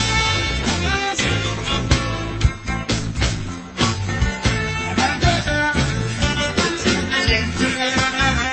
blues_blues.00000.mp3